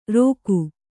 ♪ rōku